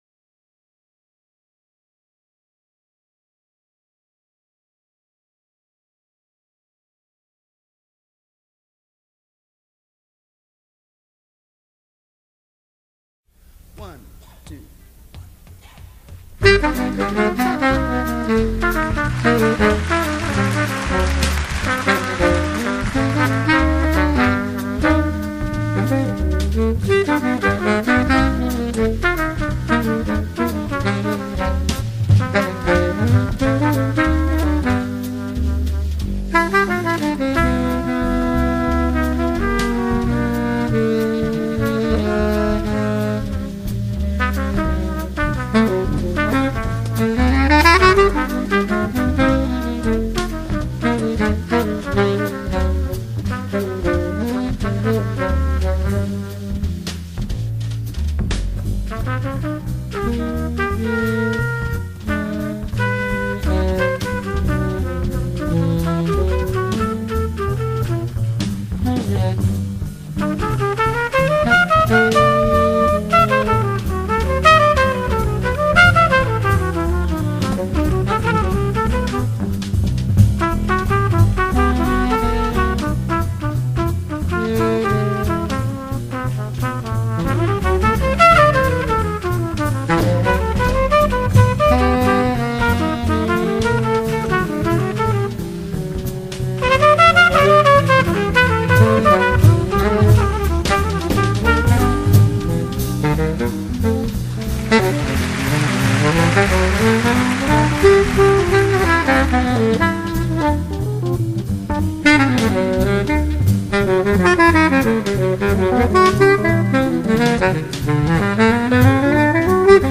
la combinació sonora del saxo baríton i la trompteta